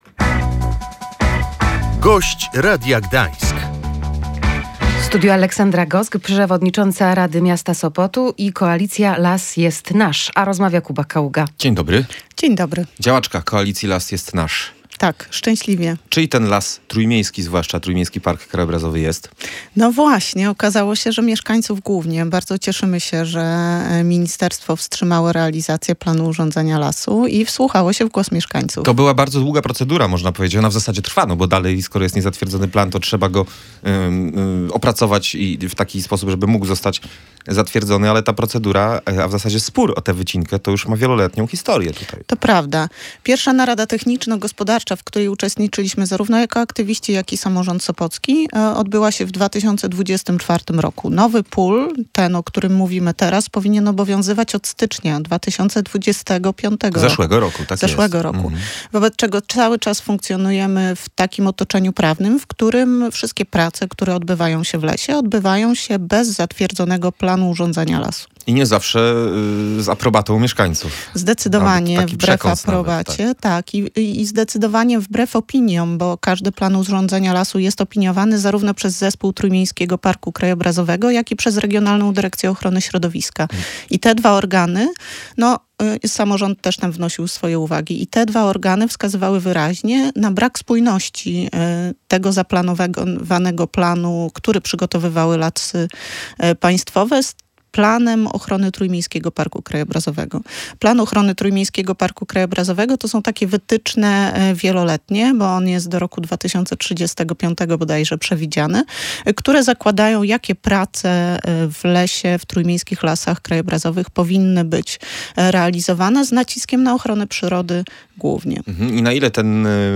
Niezatwierdzenie przez Ministerstwo Klimatu i Środowiska Planu Urządzania Lasu w Nadleśnictwie Gdańsk to dobra decyzja – mówiła w Radiu Gdańsk Aleksandra Gosk, przewodnicząca Rady Miasta Sopotu, działaczka Koalicji Las Jest Nasz.